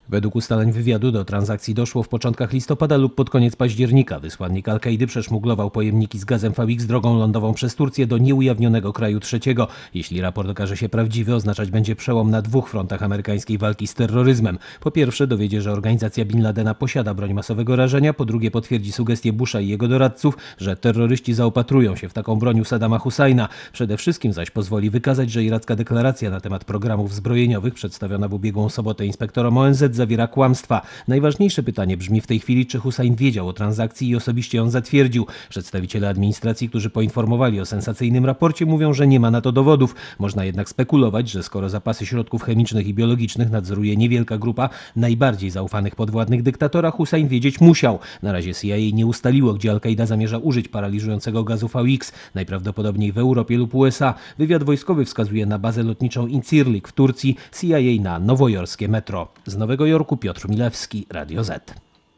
Posłuchaj relacji naszego korespondenta